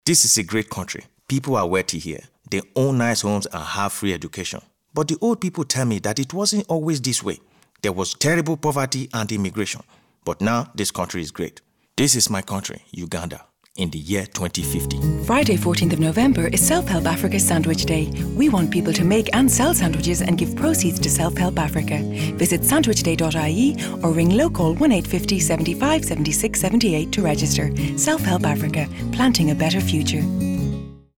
self-help-male-b.mp3